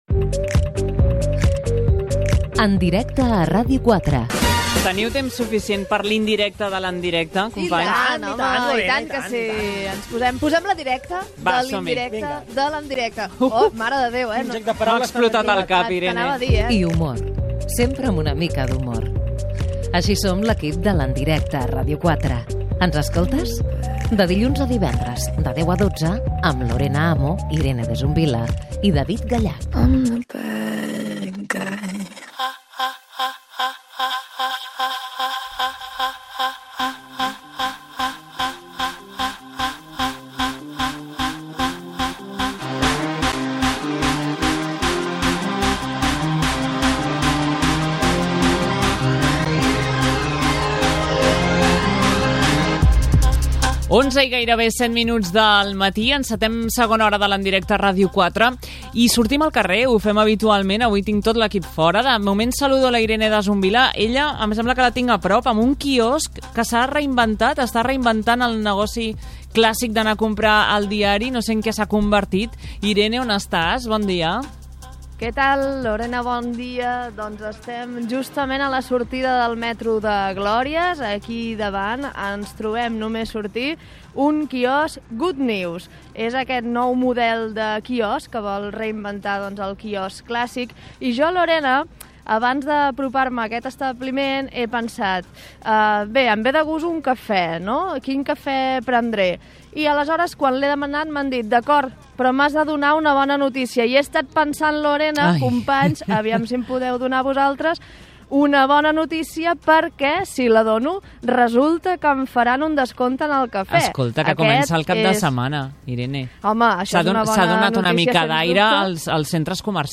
connexió amb un quiosc renovat de la Plaça de les Glòries de Barcelona (Good News)
Info-entreteniment